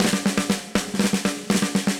AM_MiliSnareB_120-03.wav